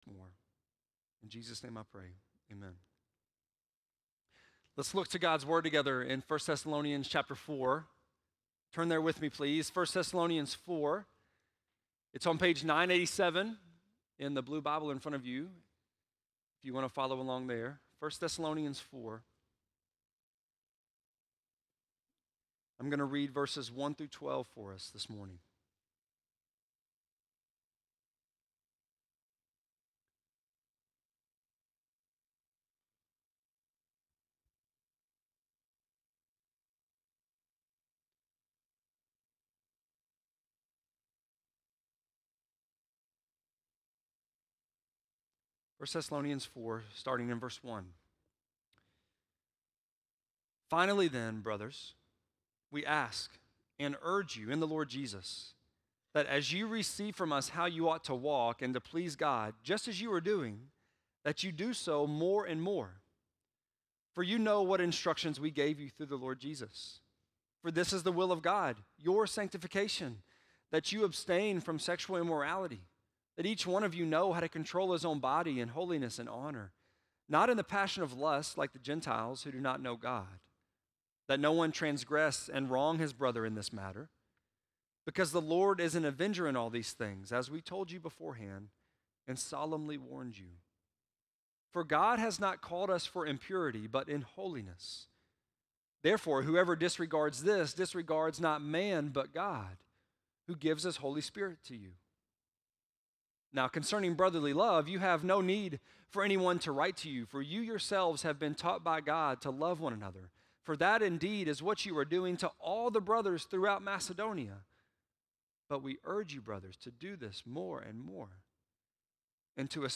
10.2-sermon.mp3